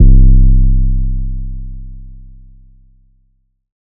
Metro Classic 808 (C).wav